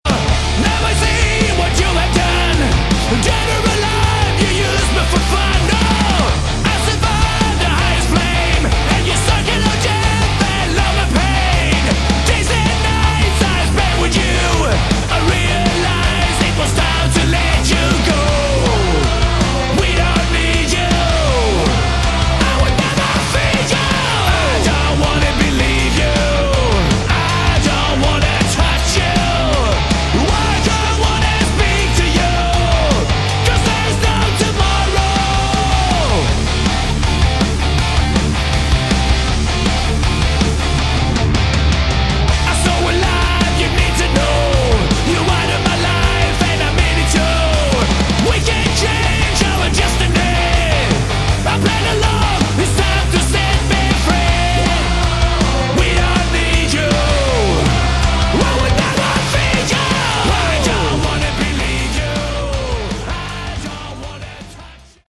Excellent sleazy hard rock!
This is good old-school dirty sleazy hard rock.